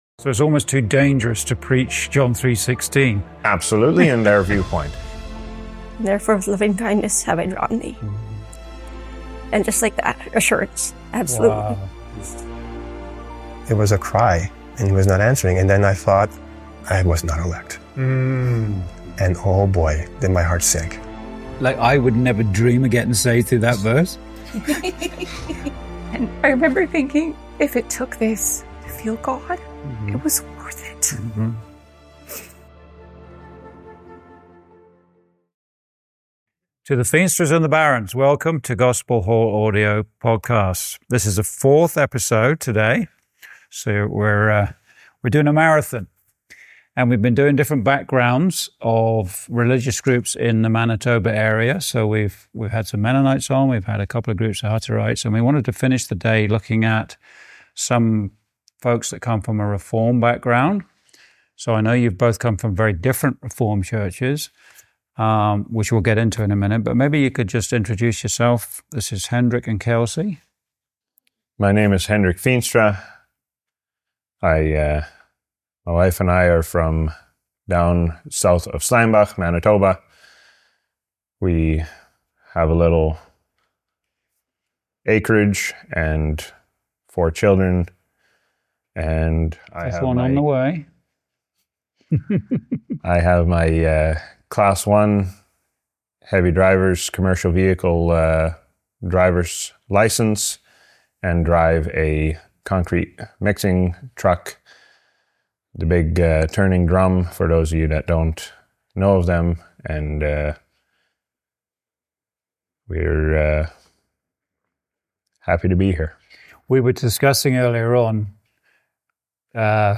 Recorded in Manitoba, Canada, 24th Feb